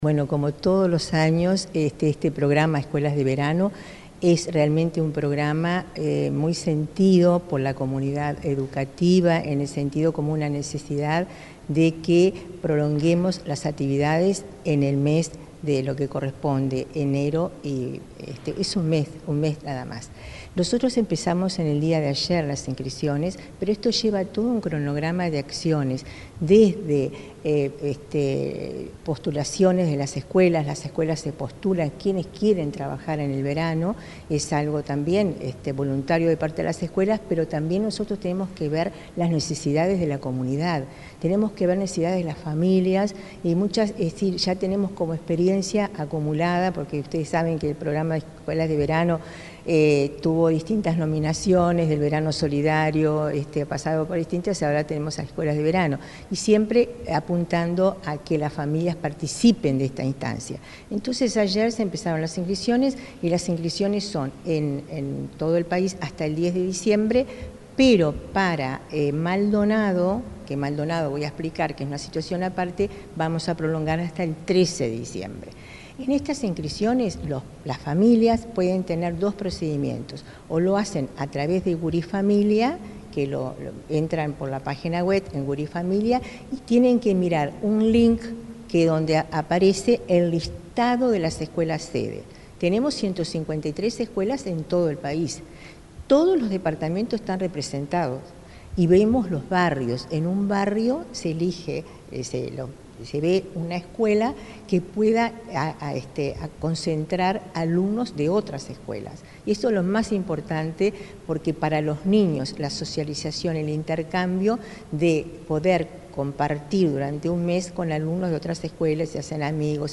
Entrevista a la directora general de Educación Inicial y Primaria, Olga de las Heras